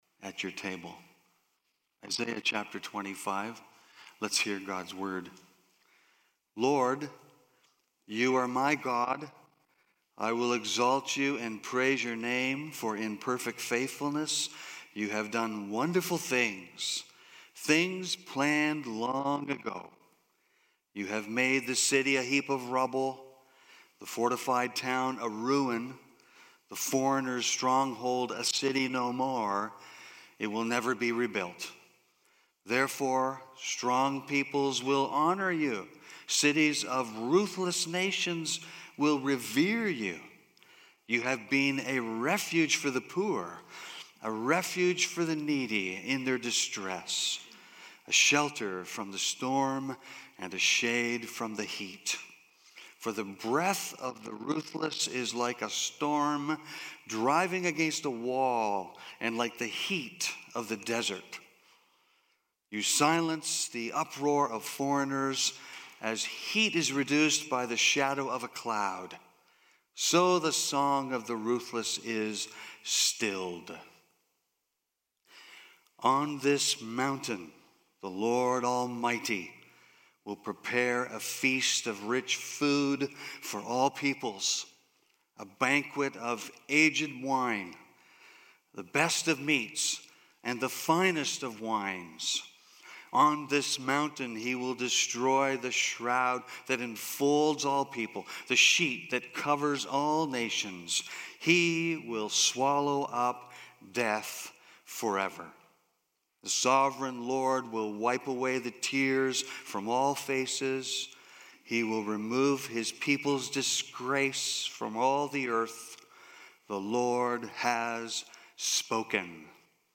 BACK TO SERMON LIST Preacher